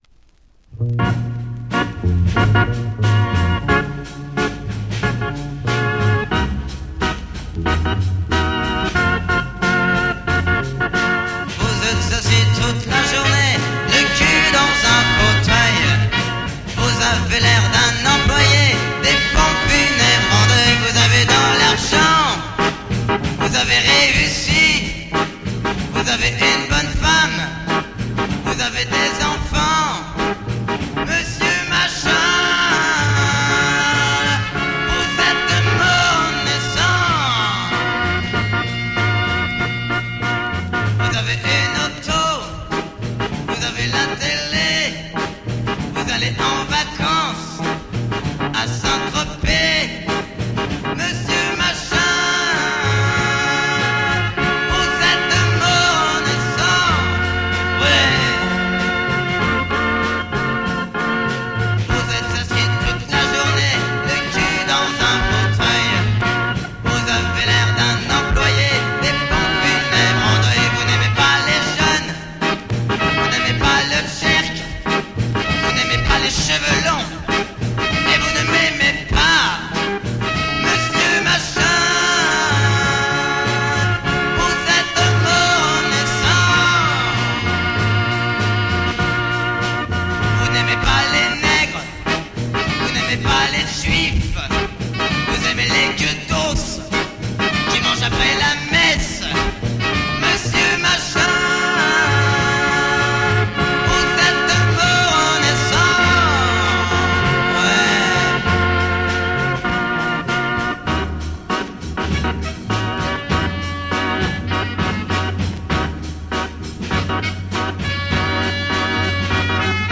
Low-Fi French garage soul